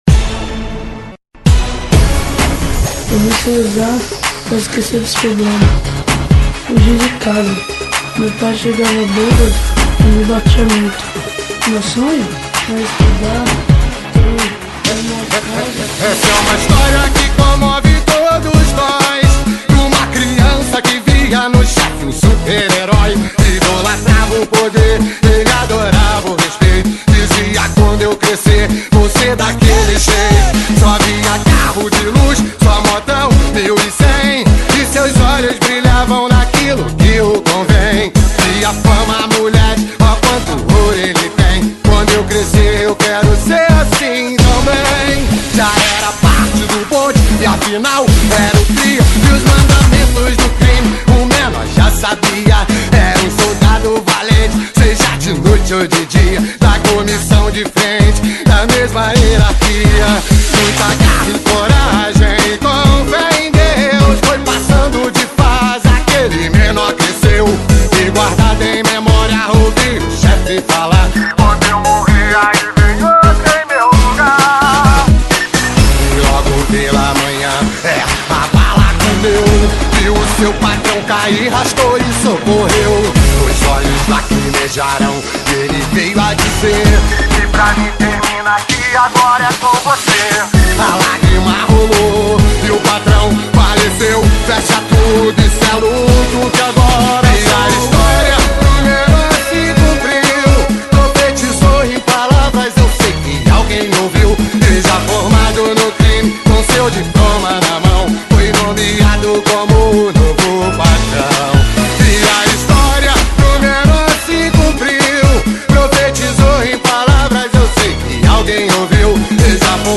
2025-02-13 21:33:12 Gênero: Funk Views